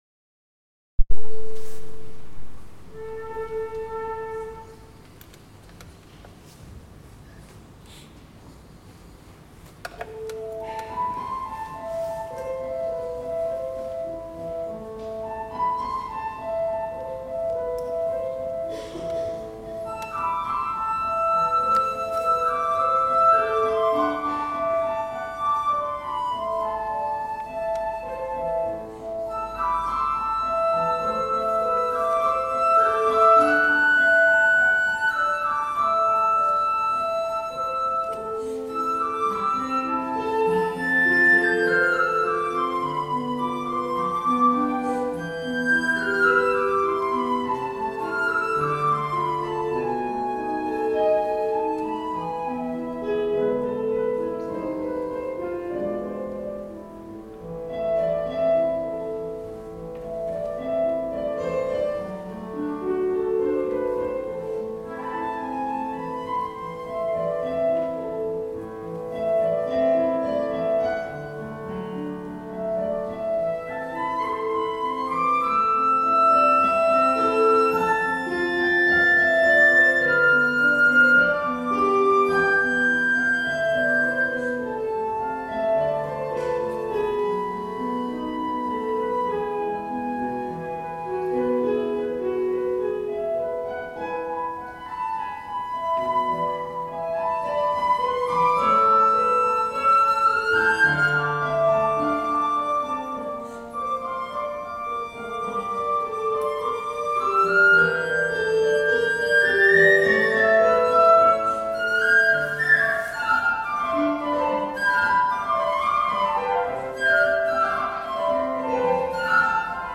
Suite Antique (Rutter) - flute solo - Take Note Concert Dec 2016